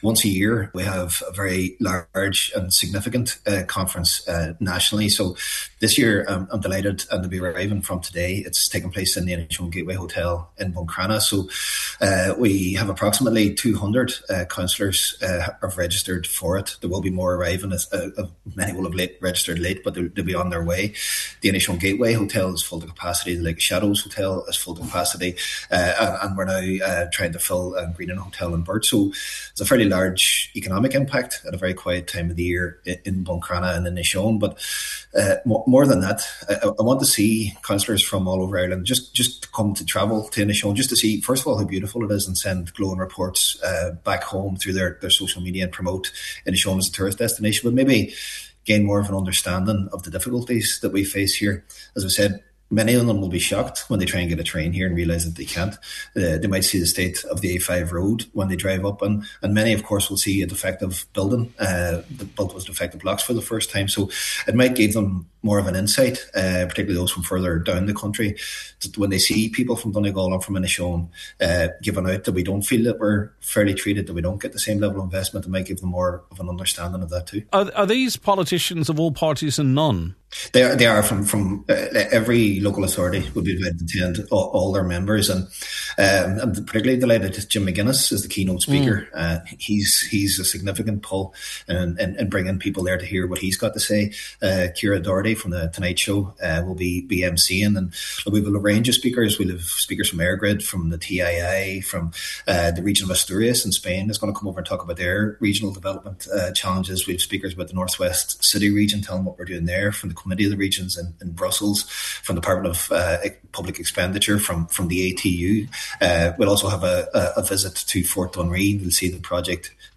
Local Councillor Jack Murray is Donegal County Council’s representative on the Committee of the Regions.